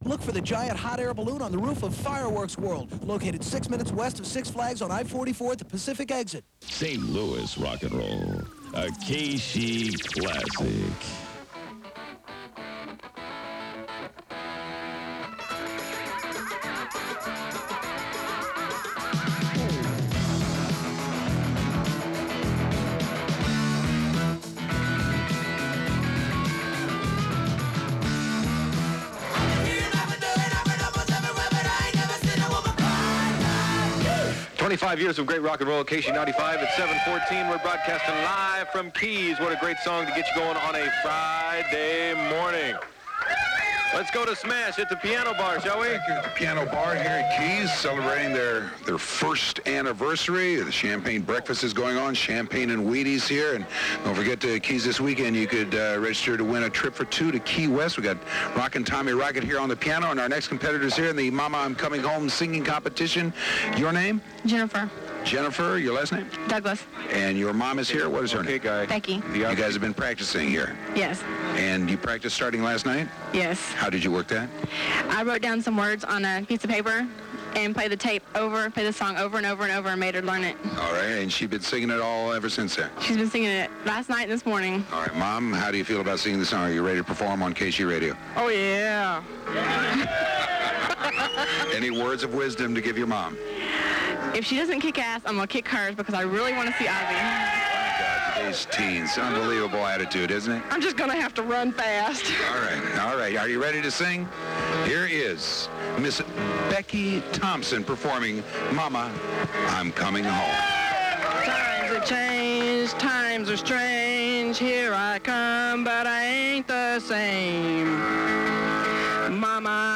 KSHE Morning Zoo Aircheck · St. Louis Media History Archive